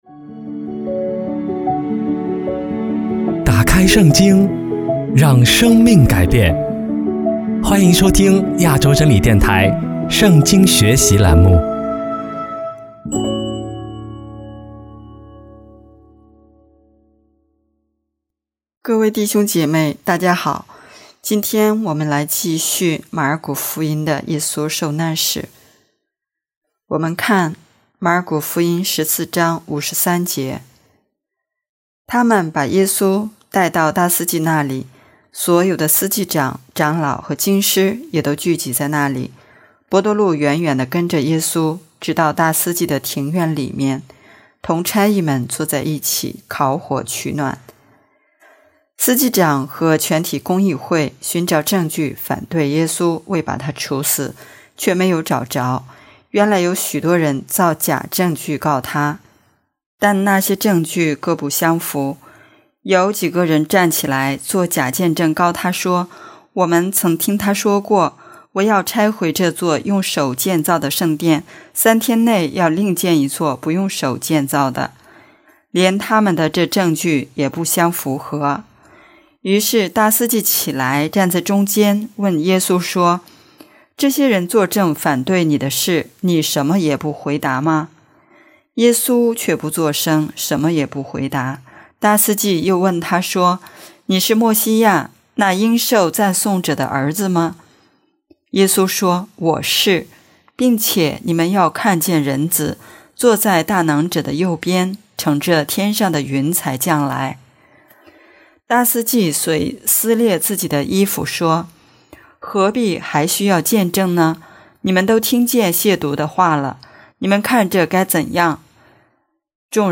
【圣经课程】|马尔谷福音第十五讲